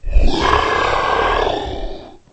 龙的声音 " 龙的咆哮 轻度 4
描述：为制作史瑞克而制作的龙声。使用Audacity录制并扭曲了扮演龙的女演员的声音。
标签： 生物 发声 怪物
声道立体声